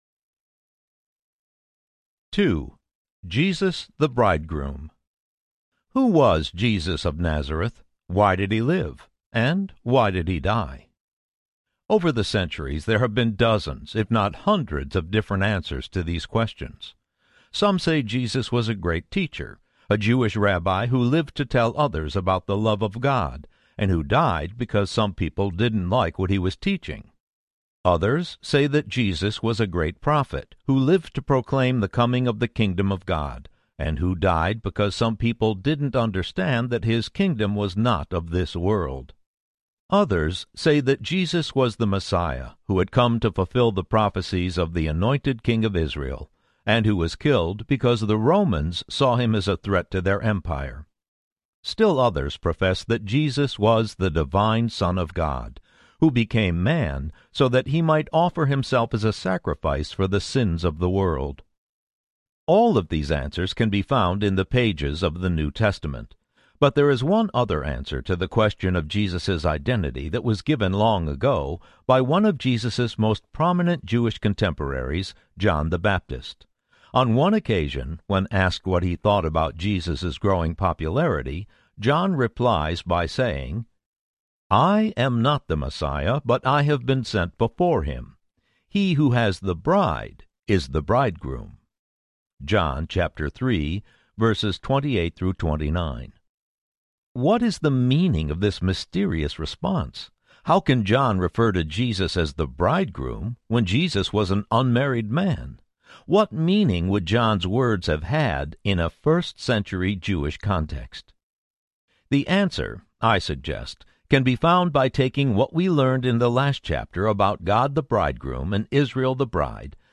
Jesus the Bridegroom Audiobook
Narrator
6.0 Hrs. – Unabridged